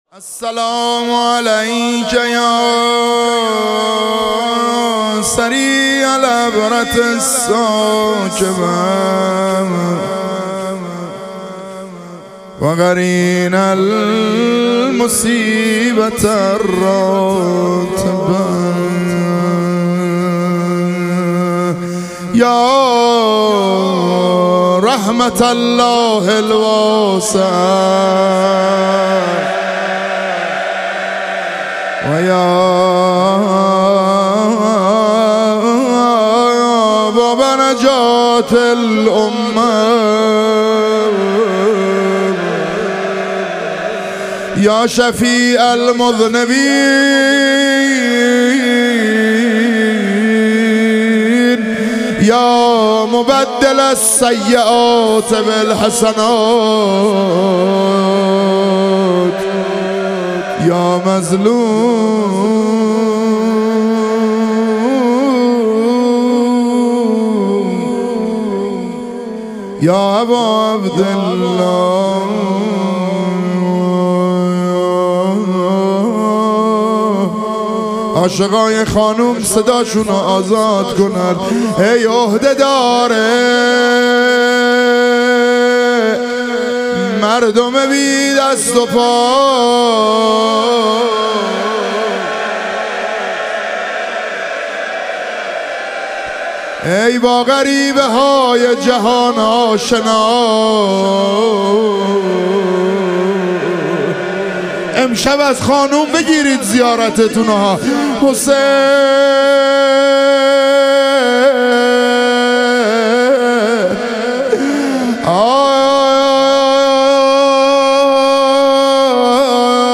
شهادت حضرت زینب کبری علیها سلام - روضه